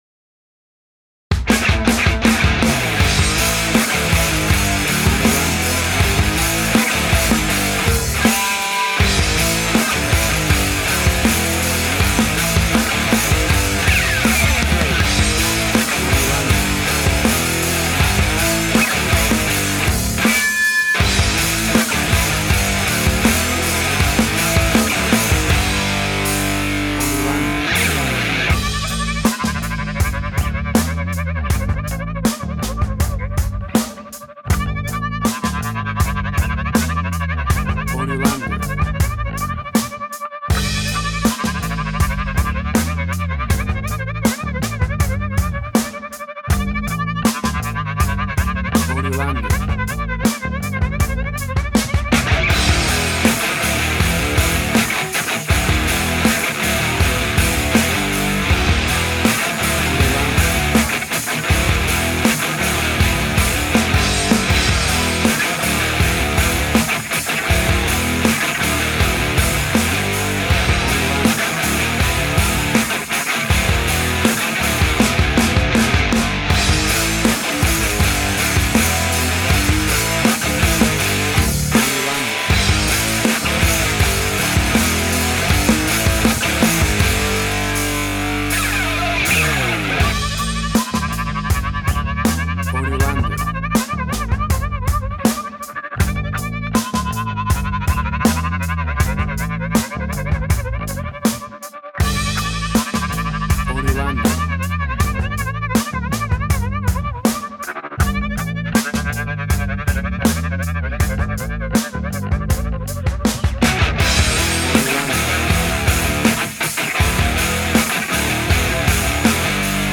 WAV Sample Rate: 16-Bit stereo, 44.1 kHz
Tempo (BPM): 80